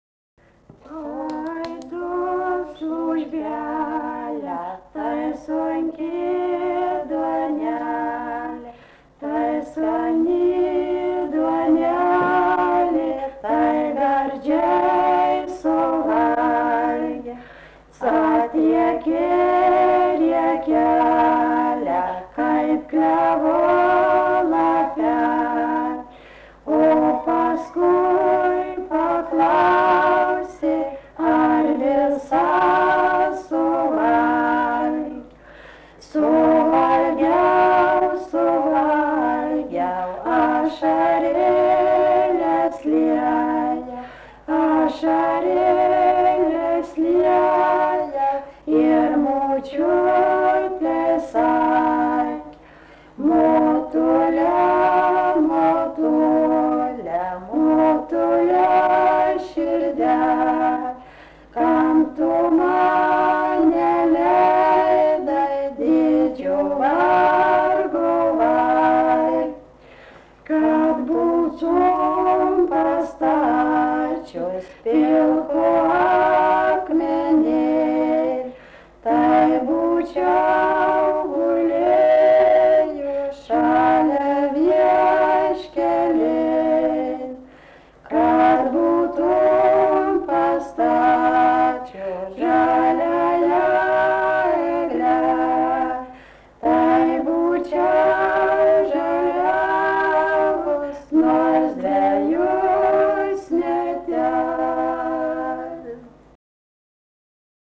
Šunkariai
vokalinis